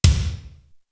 BIG T0M 1.wav